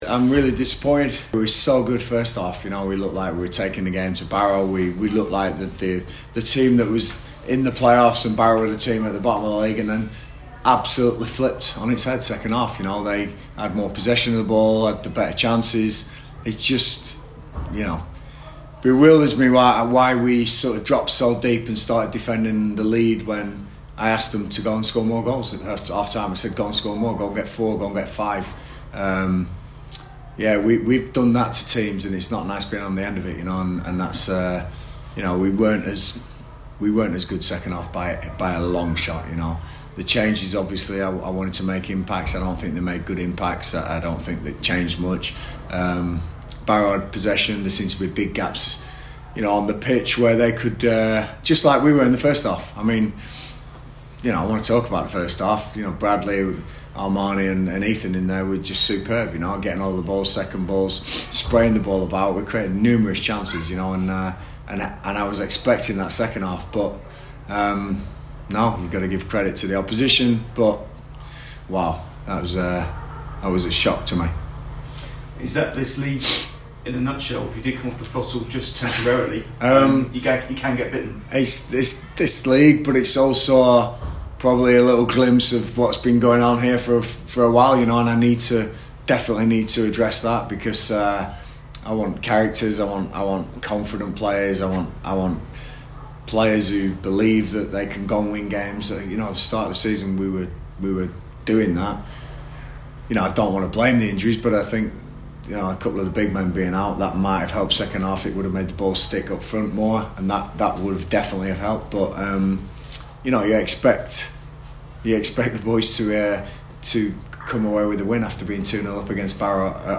LISTEN: Gillingham manager Gareth Ainsworth reacts to their draw against Barrow - 13/12/2025